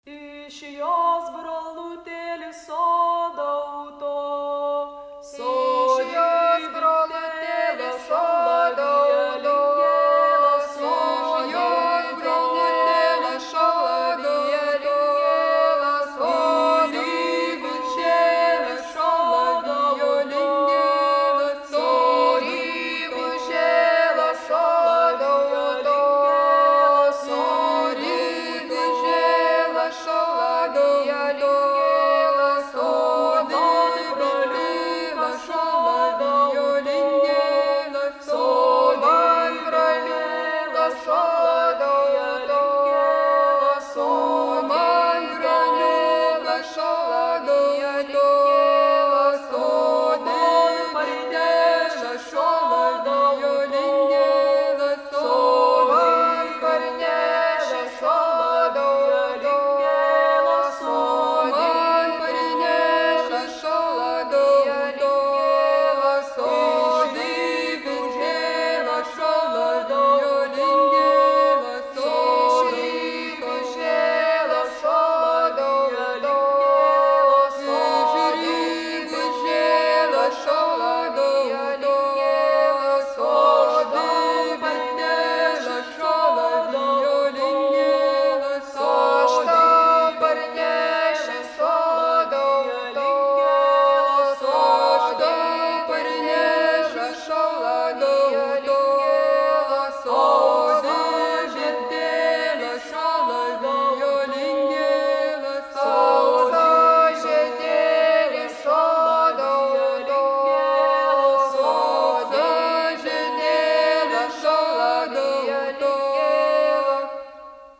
Sutartinės
All three singers perform both phrases of the melody at staggered intervals, continuing until they have sung the entire text of the song.